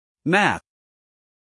math-stop-us-male.mp3